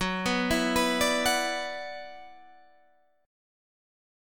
F#7sus4#5 chord